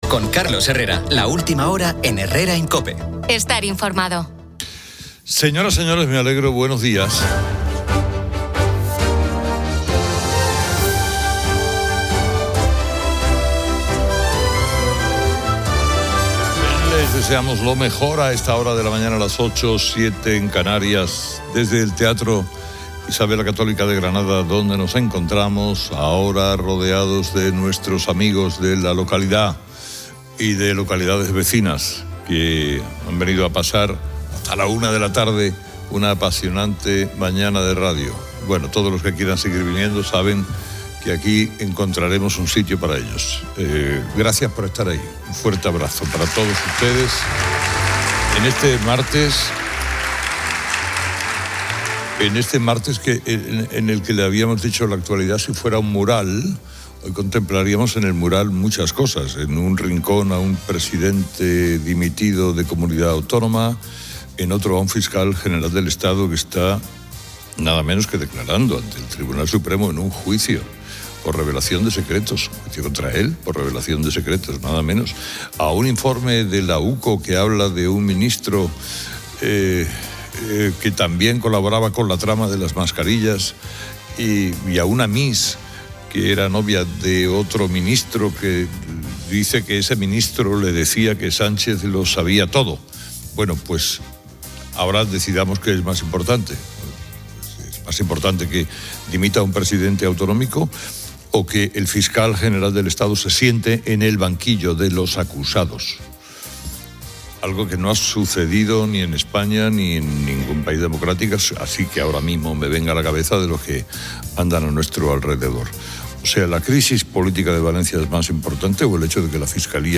Carlos Herrera desde COPE en Granada aborda la dimisión de Carlos Mazón, coincidiendo con el histórico juicio al Fiscal General del Estado, Álvaro...